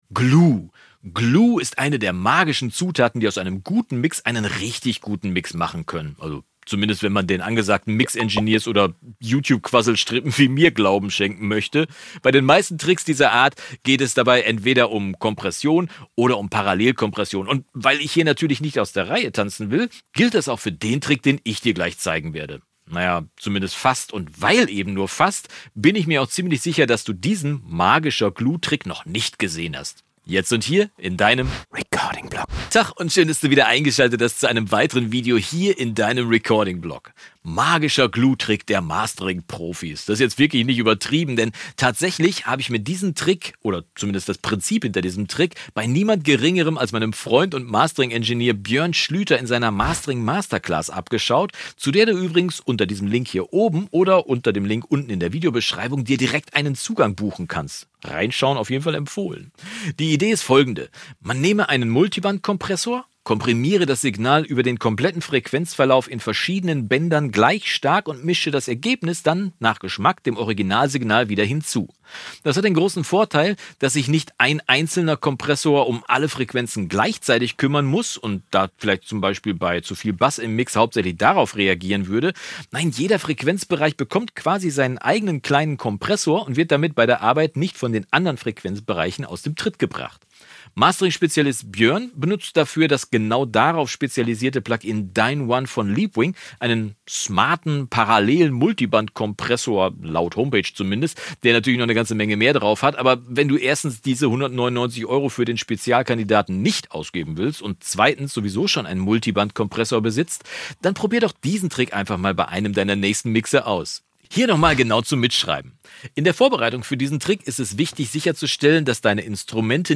Mega Glue-Trick der Mastering-Profis | Tutorial | Recording-Blog MP171